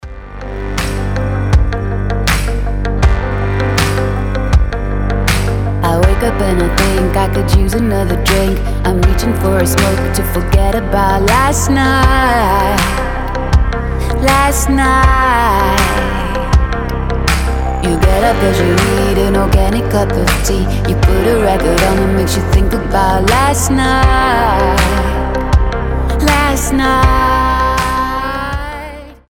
атмосферные
женский голос
indie pop
Trip-Hop